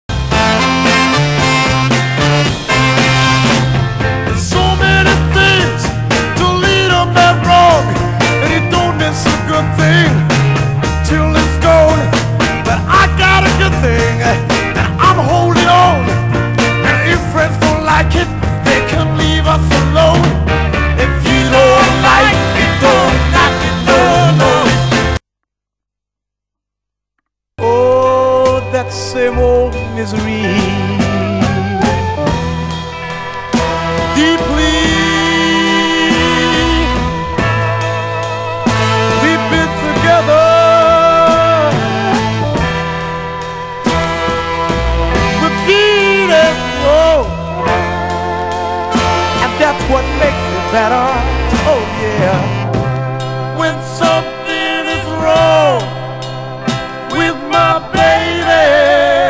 (税込￥8800)   UK SOUL